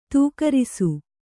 ♪ tūkarisu